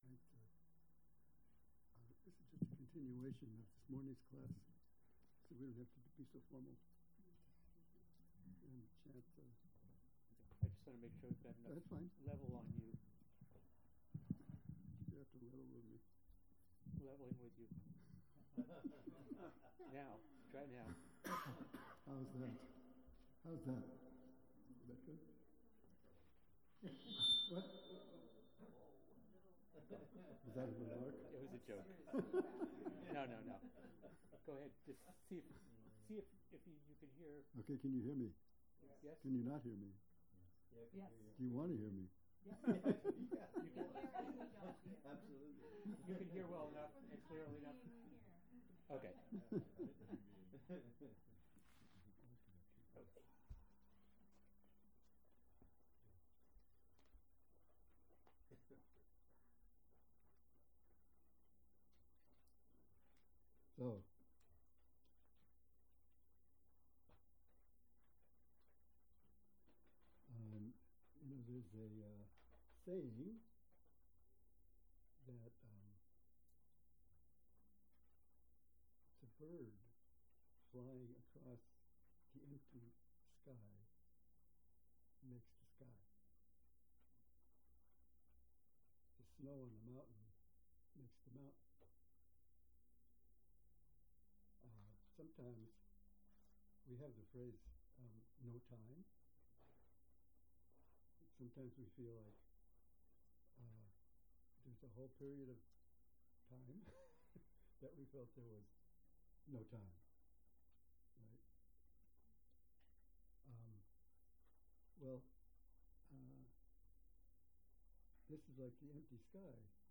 Dharma Talk Study Sesshin